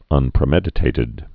(ŭnprĭ-mĕdĭ-tātĭd)